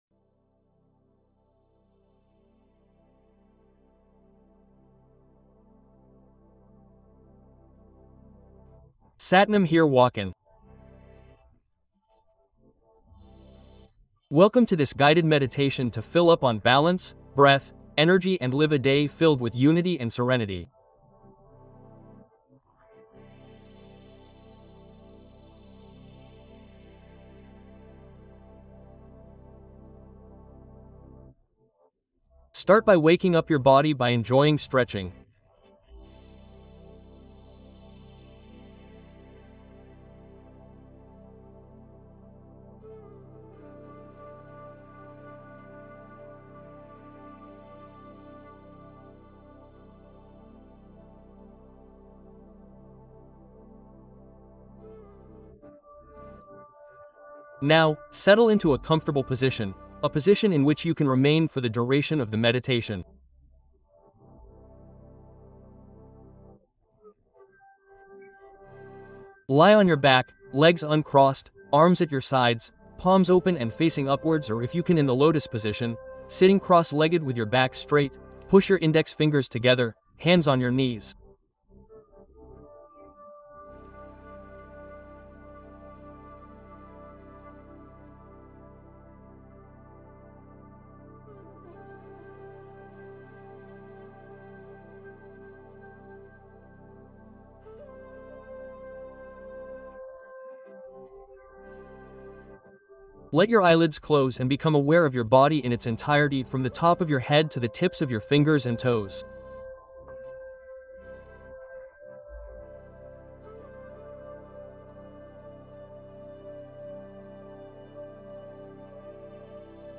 Pleine conscience guidée : alléger le mental et créer de l’espace intérieur